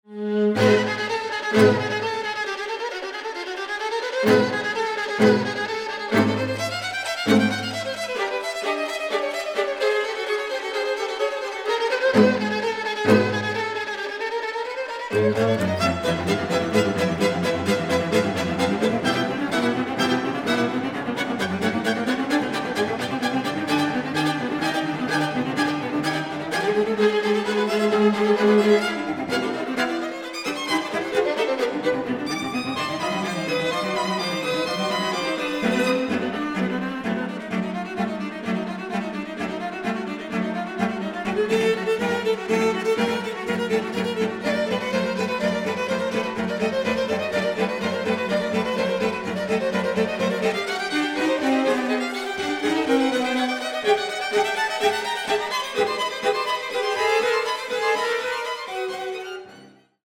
String Quartet No. 8 in C minor, Op. 110